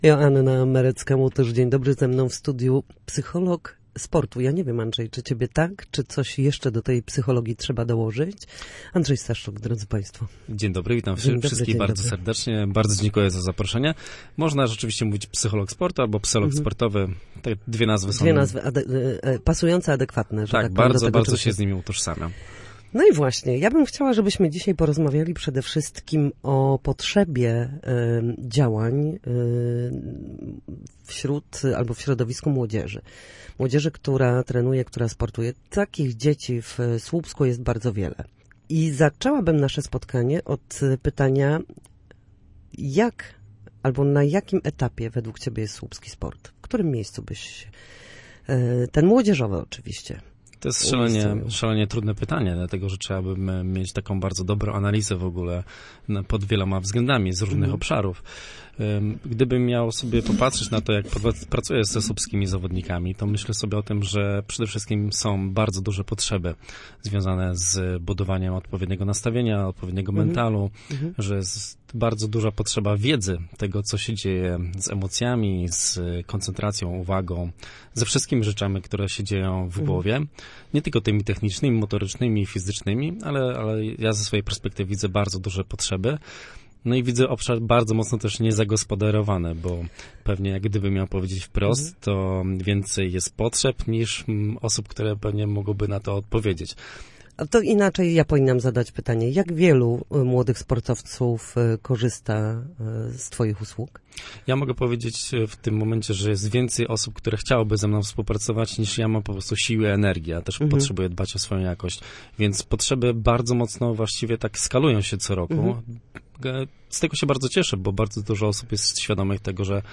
W każdą środę, w popołudniowym Studiu Słupsk Radia Gdańsk dyskutujemy o tym, jak wrócić do formy po chorobach i urazach.